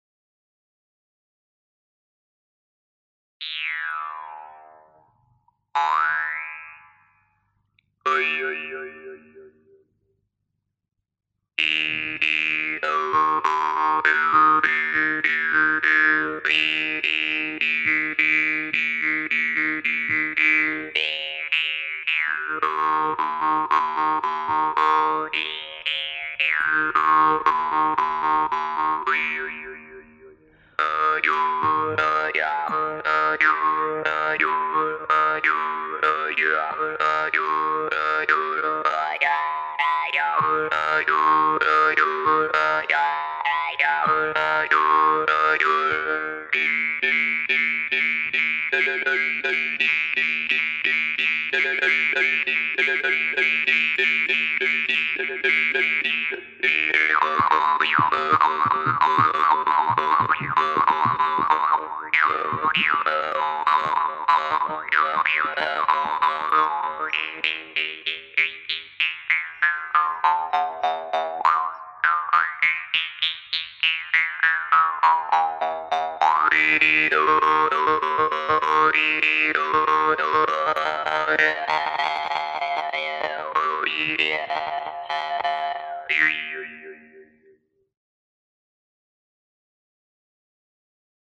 • Категория: Wargan